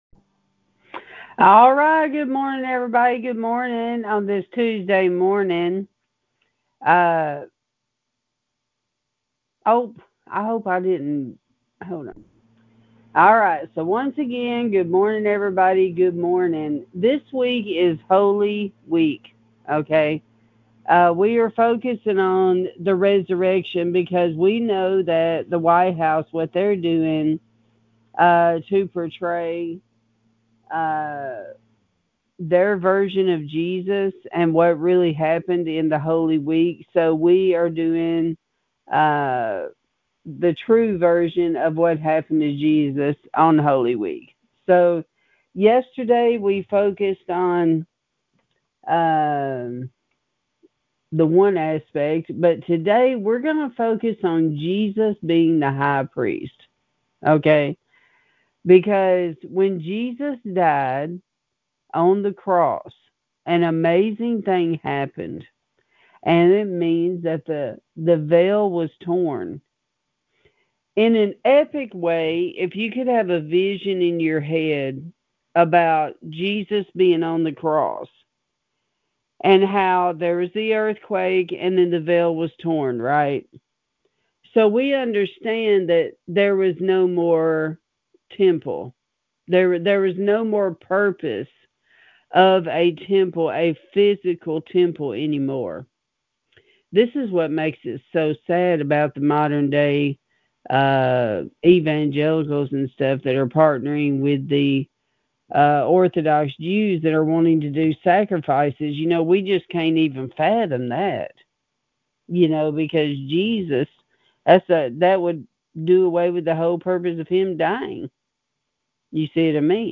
Sermons | Garden of Eden Ministries